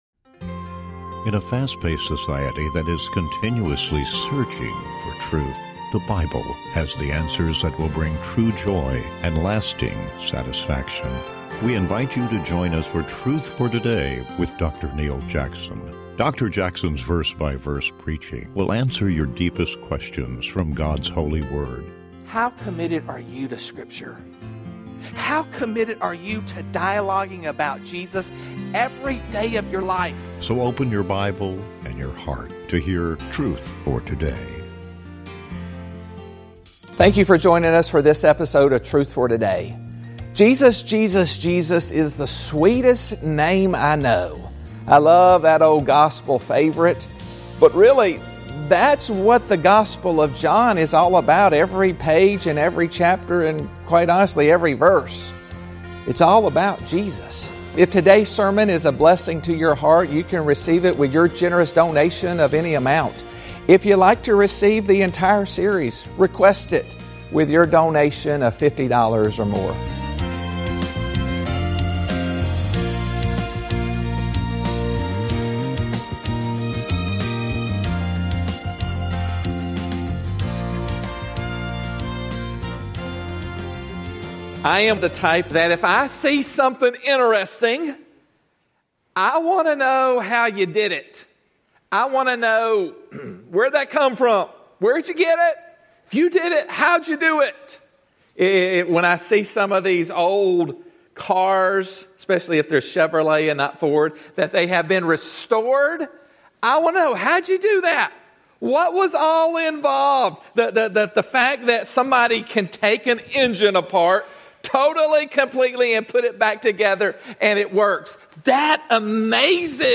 Truth for Today is committed to providing a broadcast that each week expounds God’s Word in a verse-by-verse chapter-by-chapter format.